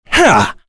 Kain-Vox_Attack1.wav